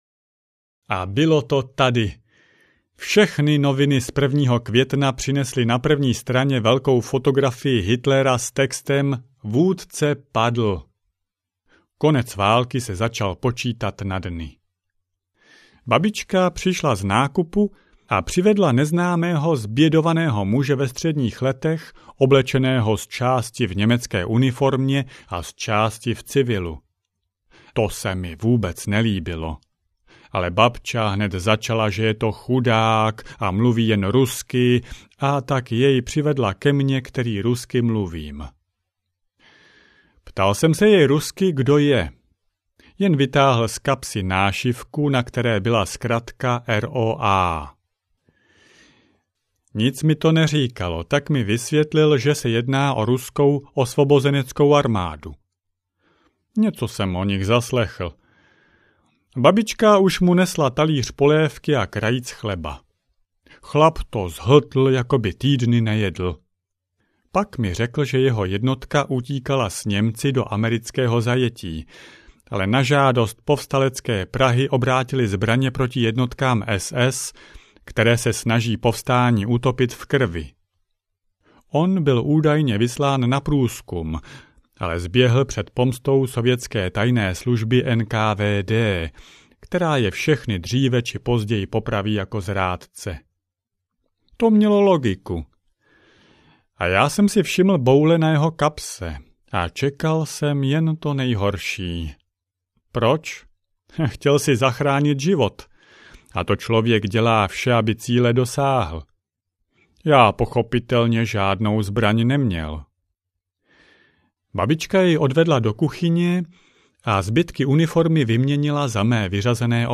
Ukázka z knihy
tajny-denik-ruskeho-legionare-2-audiokniha